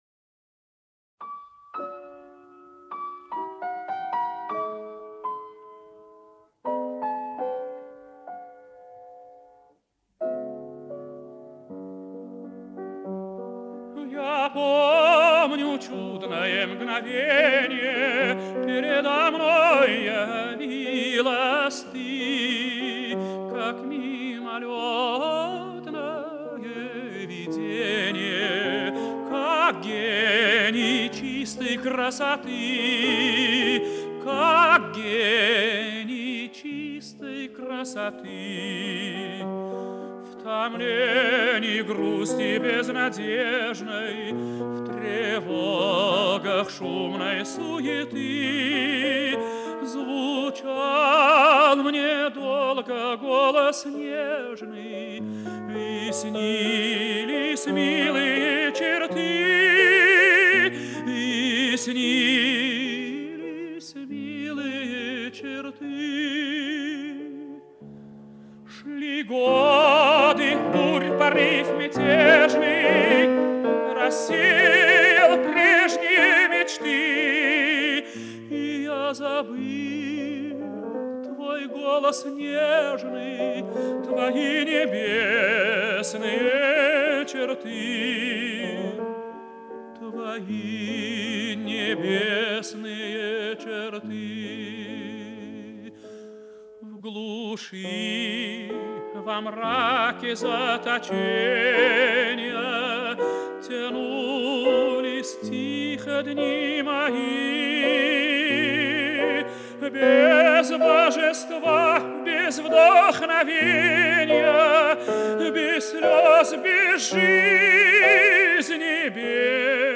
файл) 8,75 Мб Романс М.И. Глинки на слова А.С. Пушкина "Я помню чудное мгновенье". 1